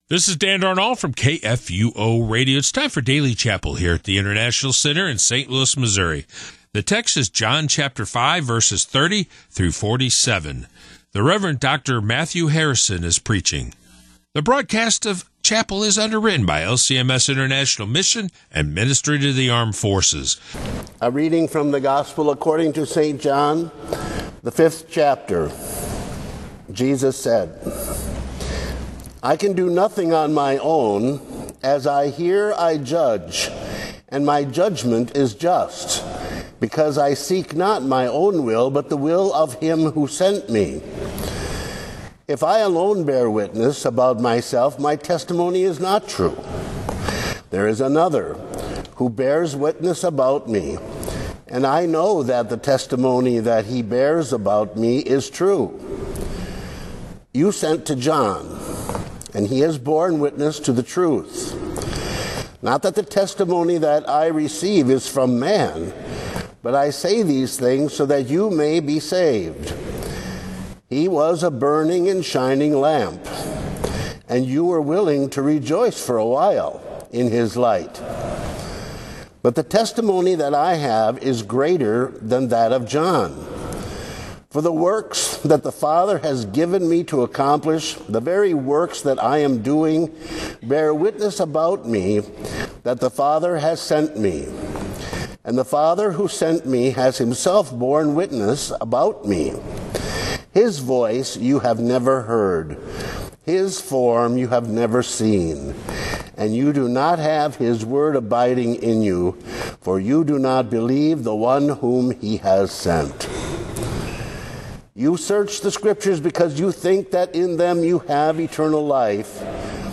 Rev. Dr. Matthew Harrison gives today's sermon based on John 5:30-47.